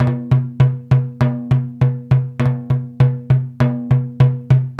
Index of /90_sSampleCDs/Spectrasonics - Supreme Beats - World Dance/BTS_Tabla_Frames/BTS_Frame Drums